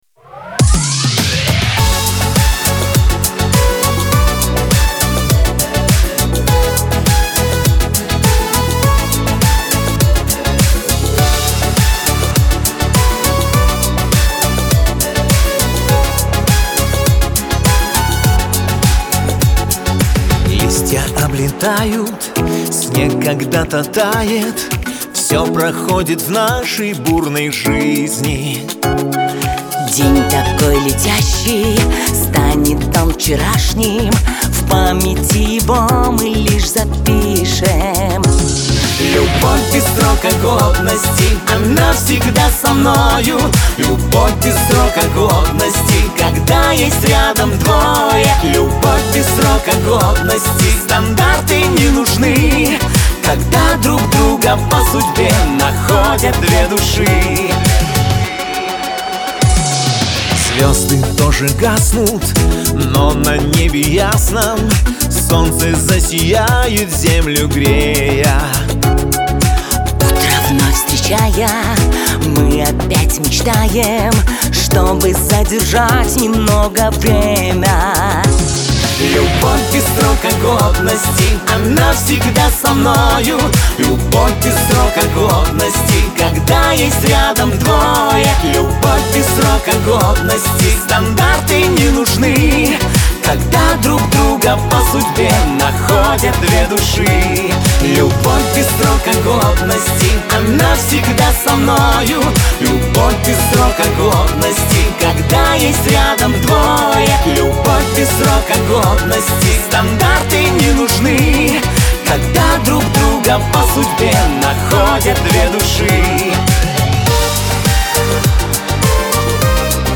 Лирика
диско , дуэт
эстрада